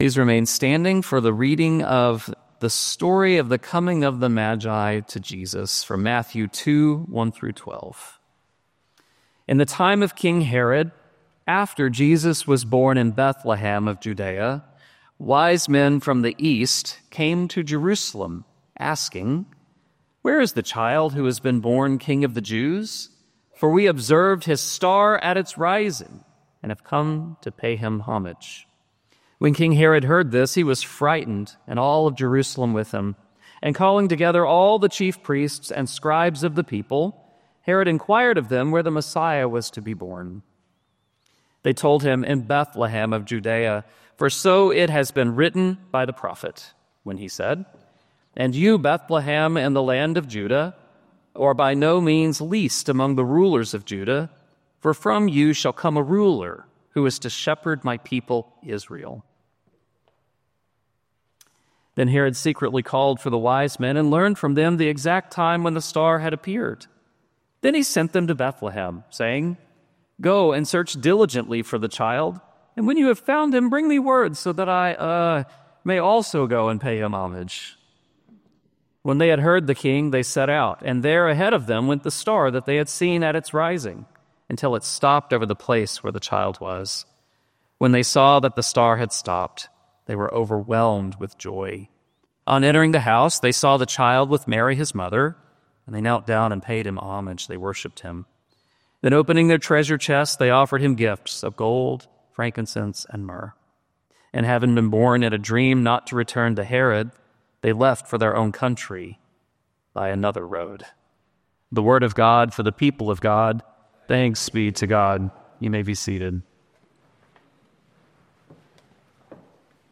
Matthew 2:1-12 Service Type: Traditional Today we remember the gifts that the Magi brought to Jesus.